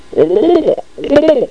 turkeys.mp3